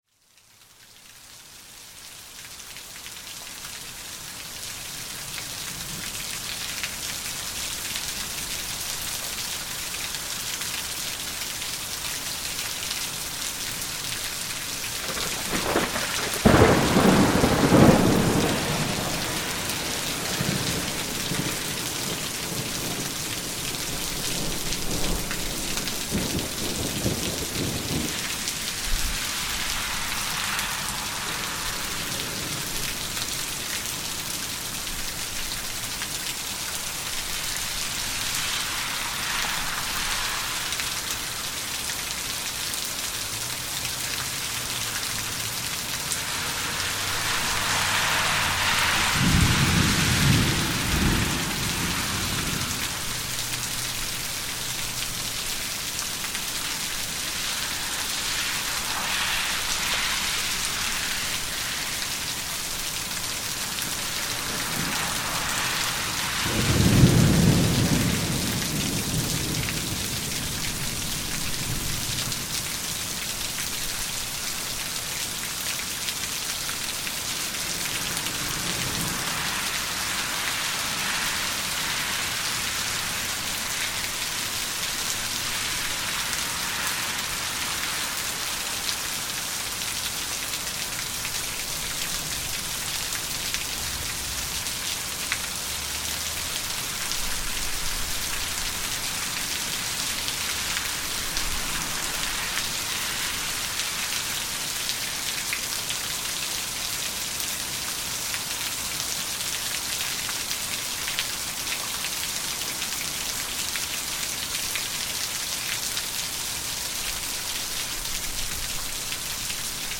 Thunderstorm at Work
A thunderstorm rolled in to work while I was on my lunch break, so naturally I went out to record part of it with the Rode iXY microphone. There is some nice stereo thunder in this one.